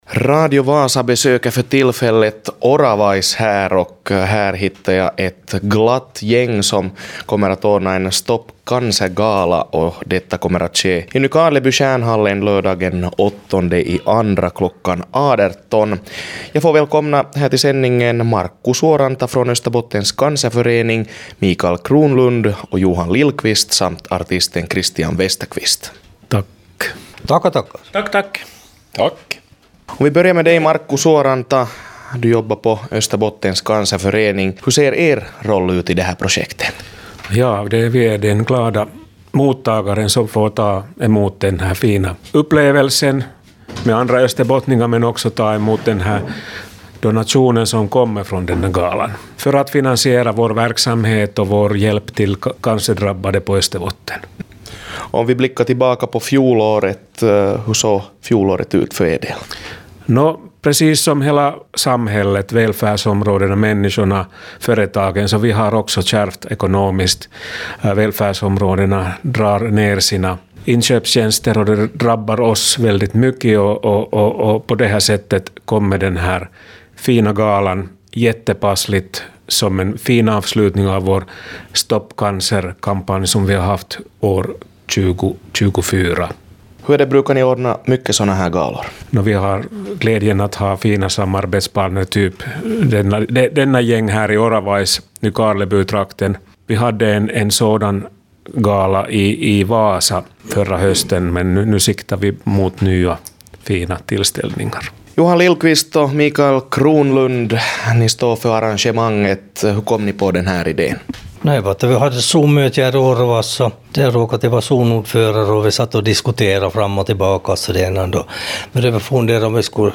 Lions klubbarna i Nykarleby och Oravaisnejden slog sina påsar ihop och bestämde att arrangera en välgörenhetskonsert för cancerdrabbade i Österbotten. I intervjun